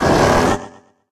Cri d'Ursaking dans Pokémon HOME.